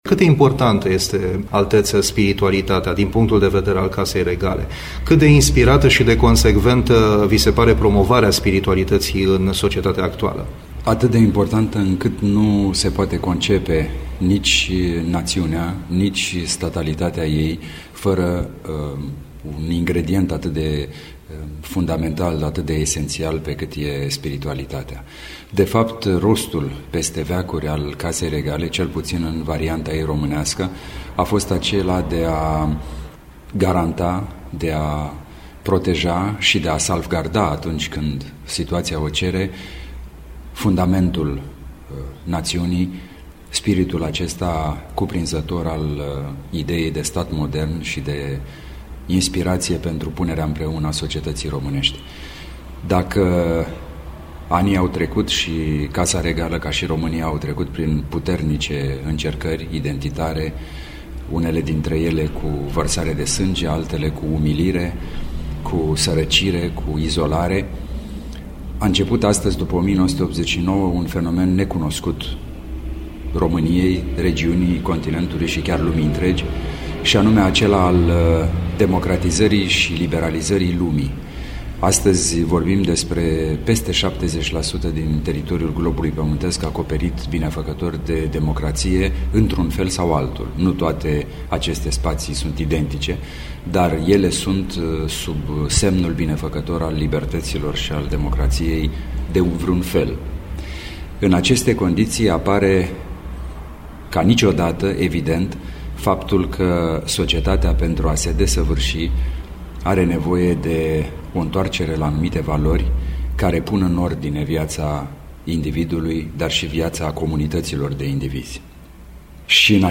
cu Alteța sa Regală Principele Radu al României